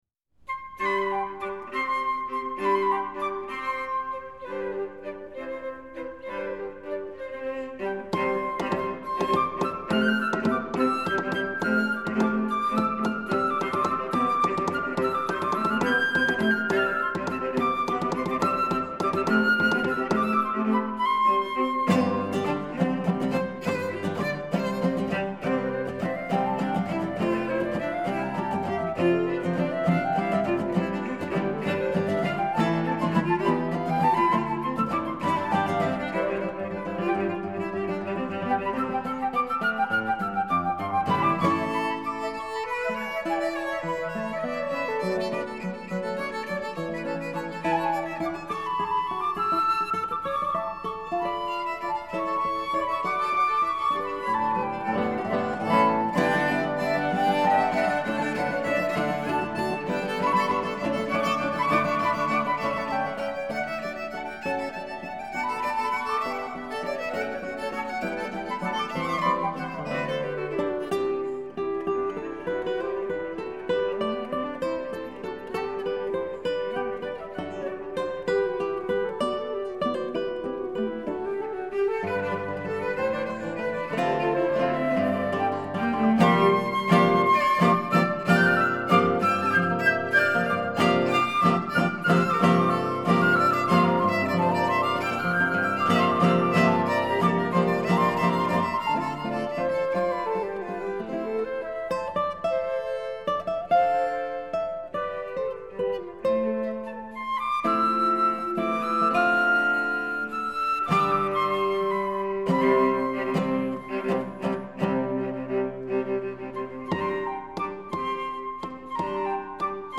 SCORING:  Flute, viola, guitar